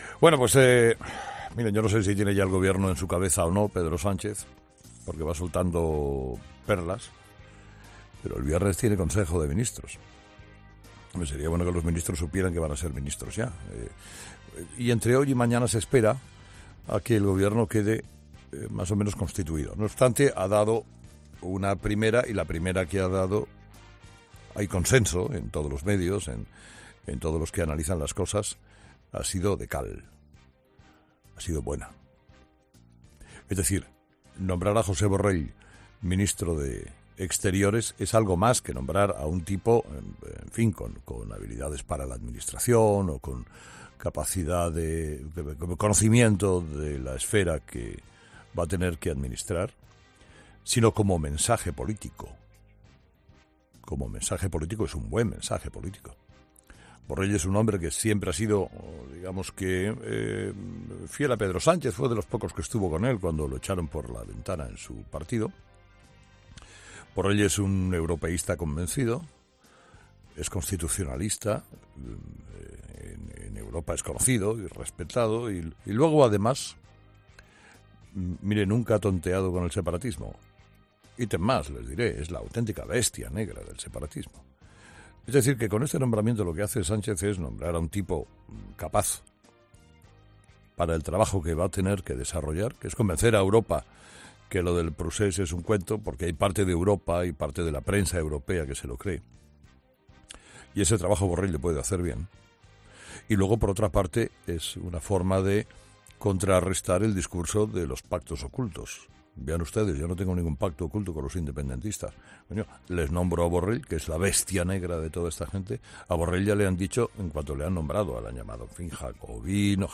Monólogo de las 8 de Herrera
"Jacobino es lo más fino que le han llamado", ha remarcado el comunicador en su monólogo de este martes